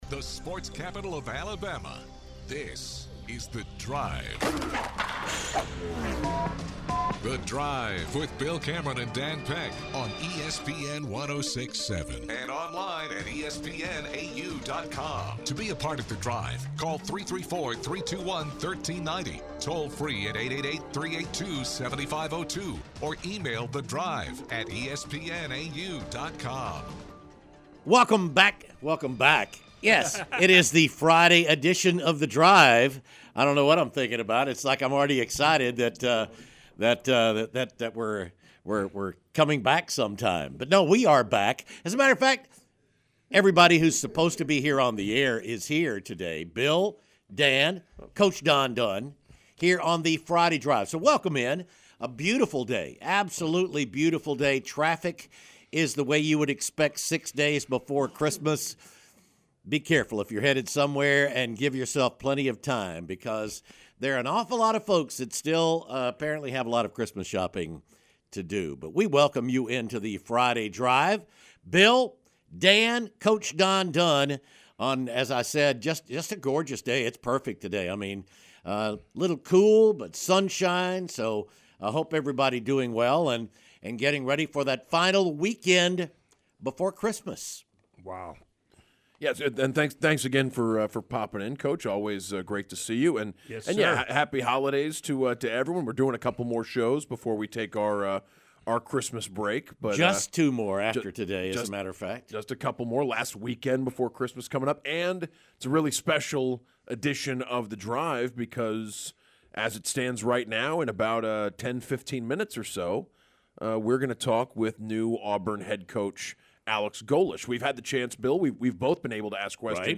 Let's Go: Auburn Coach Alex Golesh Calls the Show